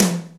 TOM TOM134.wav